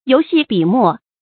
游戲筆墨 注音： ㄧㄡˊ ㄒㄧˋ ㄅㄧˇ ㄇㄛˋ 讀音讀法： 意思解釋： 指以游戲態度所寫的詩文。